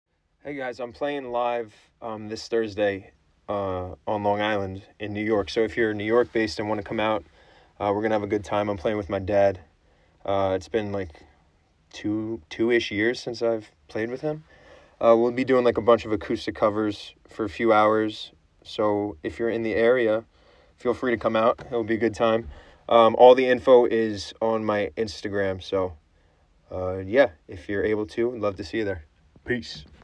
acoustic covers